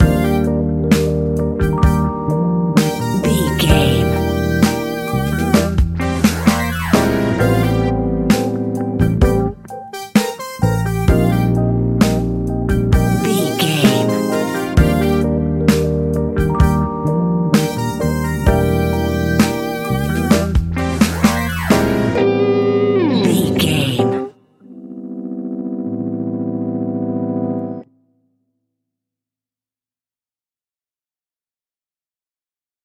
Ionian/Major
E♭
hip hop
instrumentals